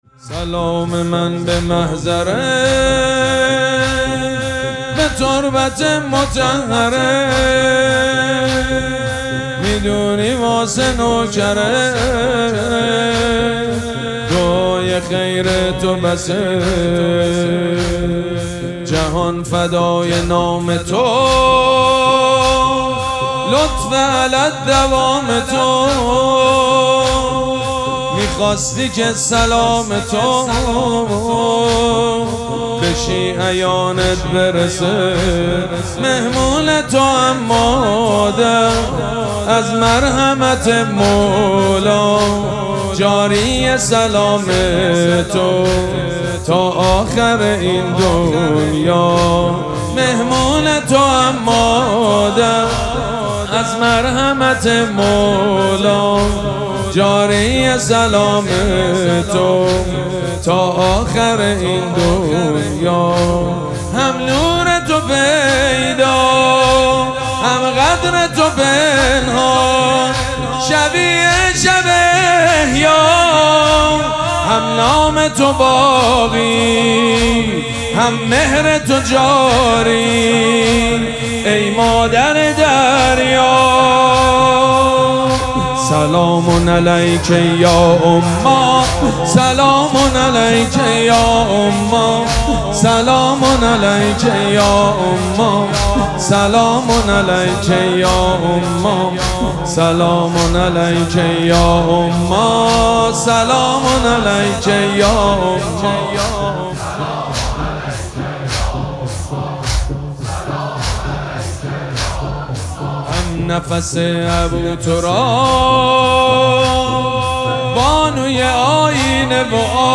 شب چهارم مراسم عزاداری دهه دوم فاطمیه ۱۴۴۶
حاج سید مجید بنی فاطمه